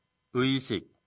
의식1 意識 [의:-]